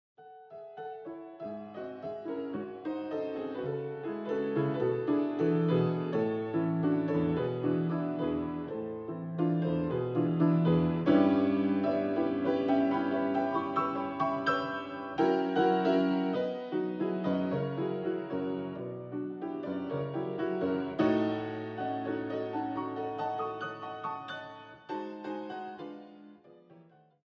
30小節と31小節、また34小節と35小節の和声に注目してお聴きください。
👇コルトー版など、今まで一般的に知られていた和声
最初の例では、メロディ部の和声が長→短と変わり、あわせて内声も変化。